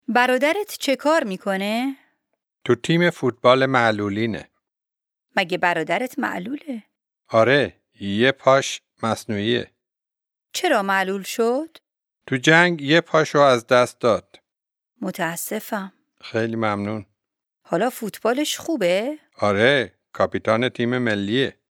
DIALOGUE 2
Dialogue2-lesson39-Farsi.mp3